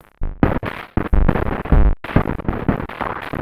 E Kit 30.wav